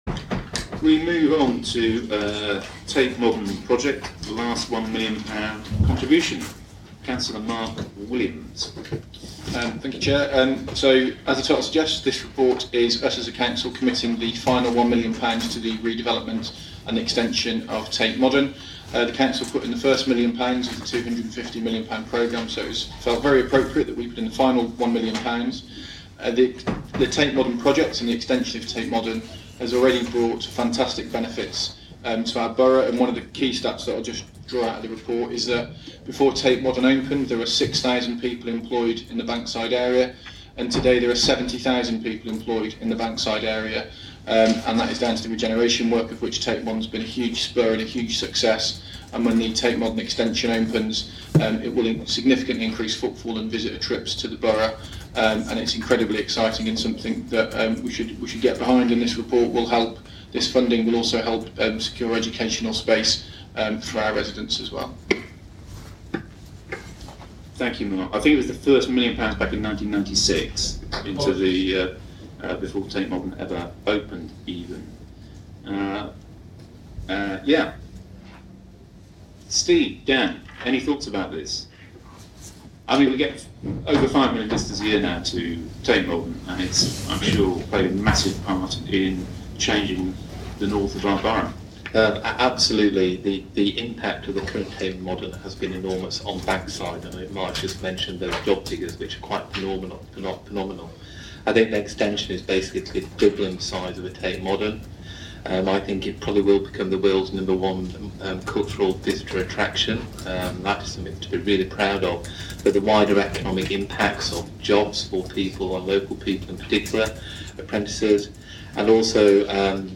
Speakers: Cllr Mark Williams (cabinet member for regeneration)